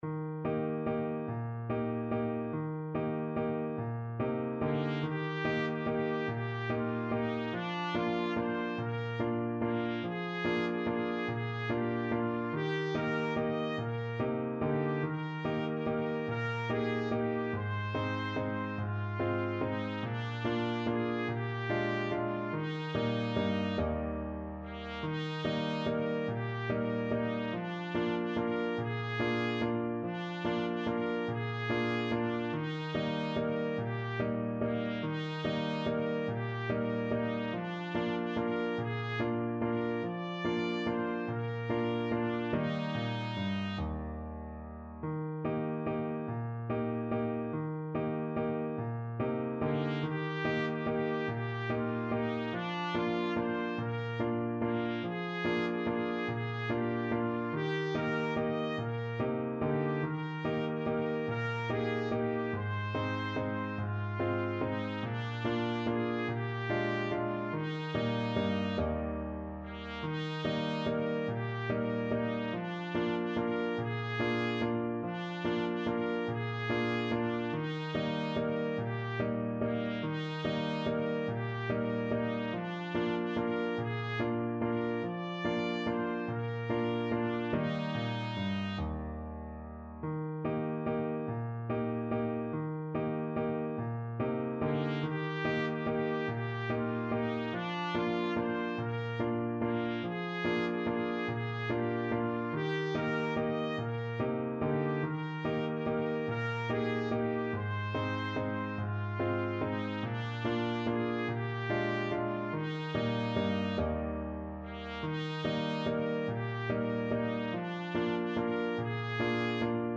Bb4-Eb6
3/8 (View more 3/8 Music)
Steady one in a bar .=c.48
Swiss